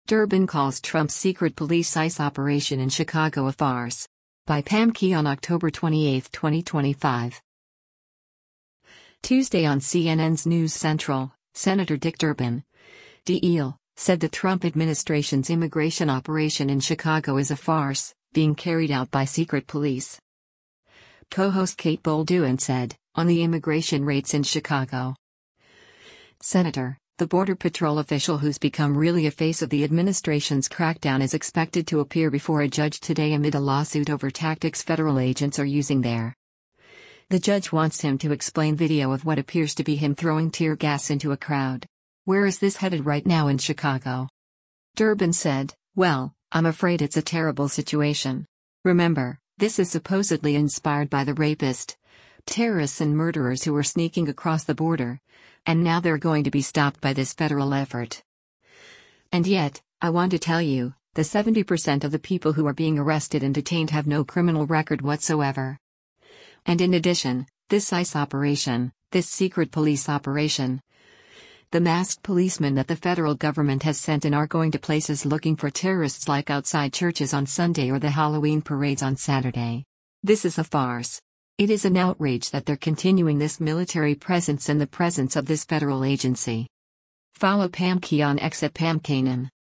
Tuesday on CNN’s “News Central,” Sen. Dick Durbin (D-IL) said the Trump administration’s  immigration operation in Chicago is a “farce,” being carried out by “secret police.”